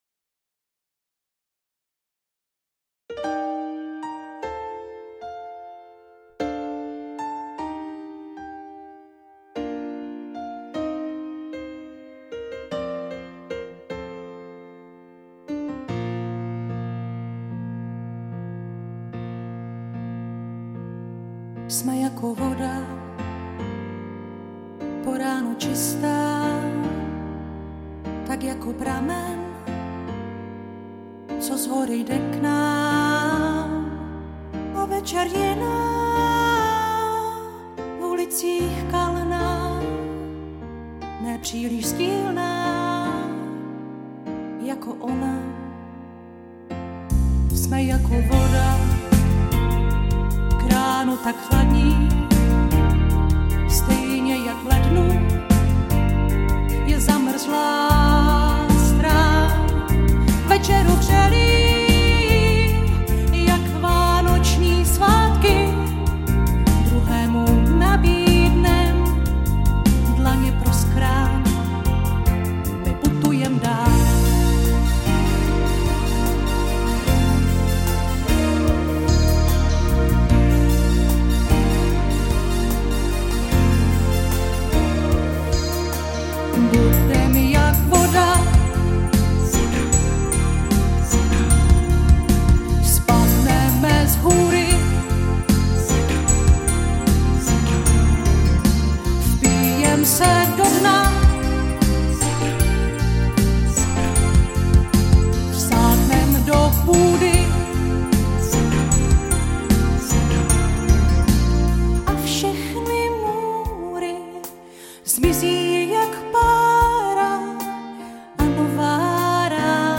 7. píseň
piccolo baskytara